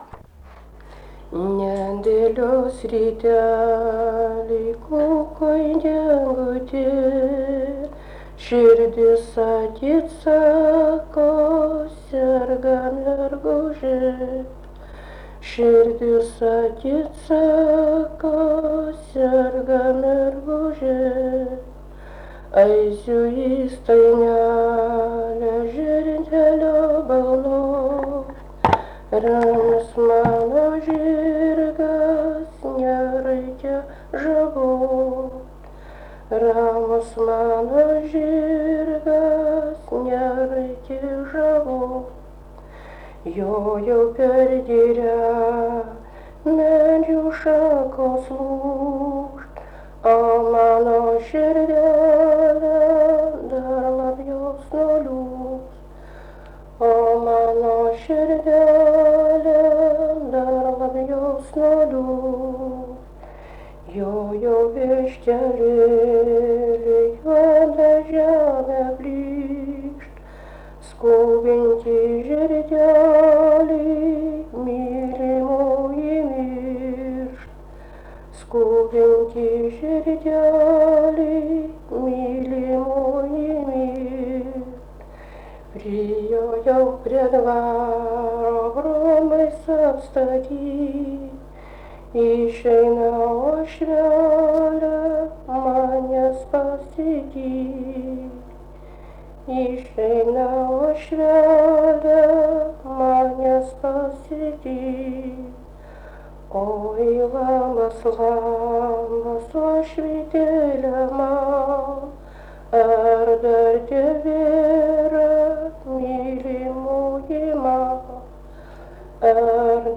daina
vokalinis